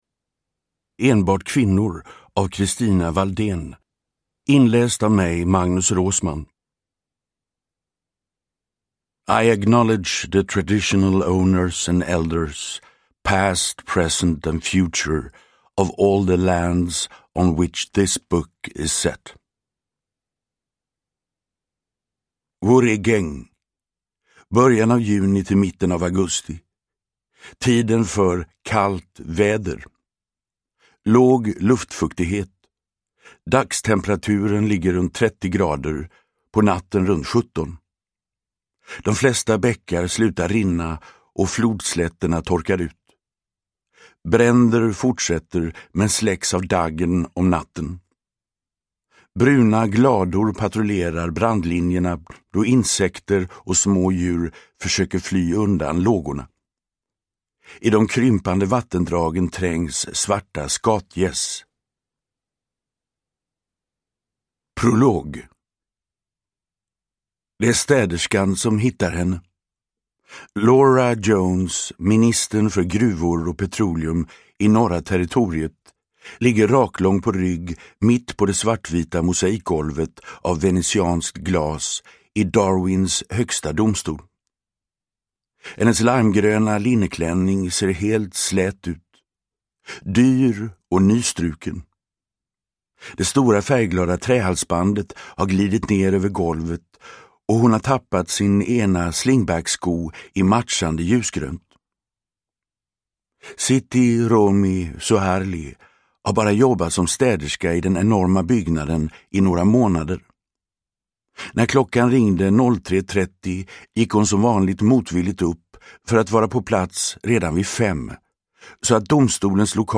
Uppläsare: Magnus Roosmann
Ljudbok